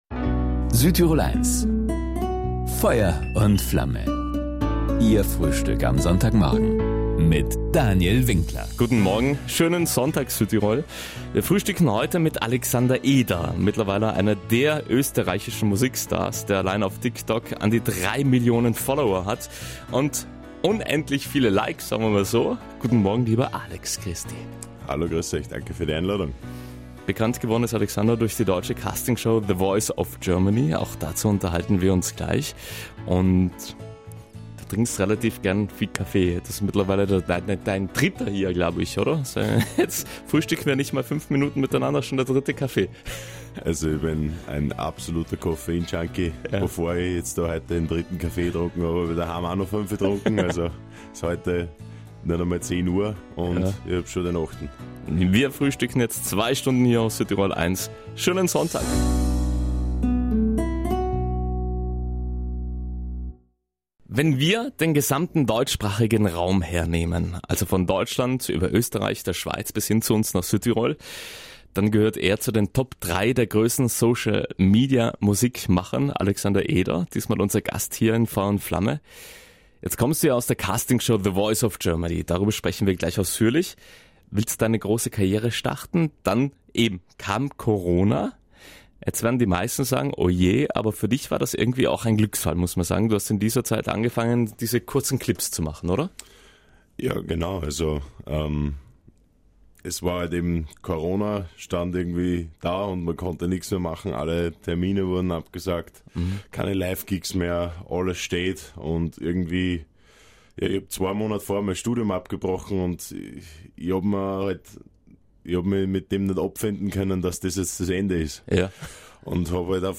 Bekannt geworden ist der österreichische Shootingstar durch die Castingshow „The Voice Of Germany“, eine Erfahrung, für die er einfach nur dankbar ist. Davon erzählte er im ausführlichen Sonntagsfrühstück auf Südtirol 1 und er verriet auch, wie er Südtirol auf zwei Rädern kennen und lieben gelernt hat.